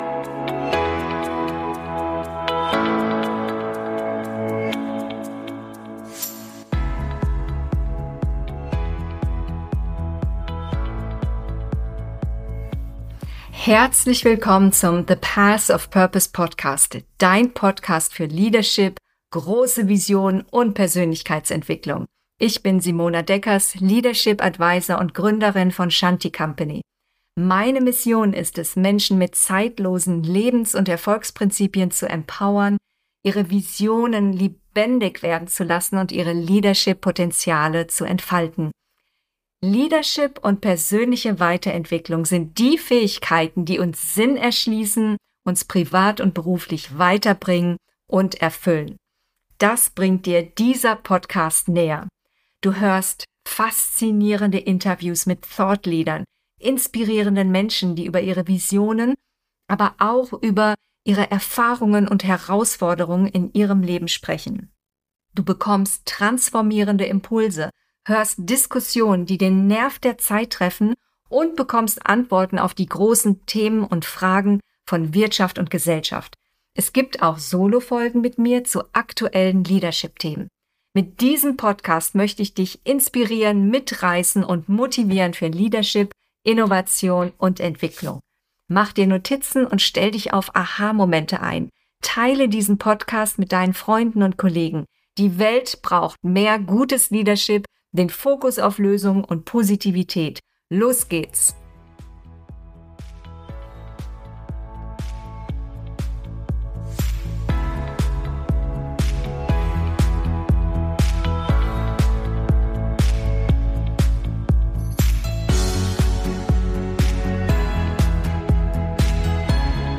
Forschung zu Familienunternehmen / Mittelstand: Innovatoren im Wandel - Interview